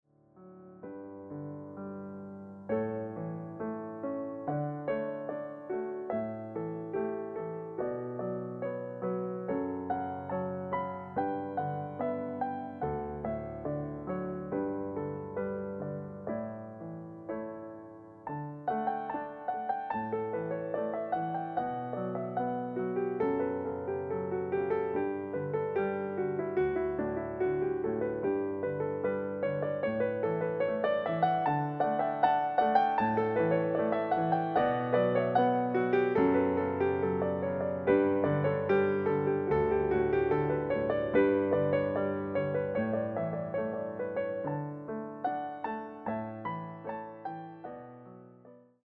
Your pianist for every event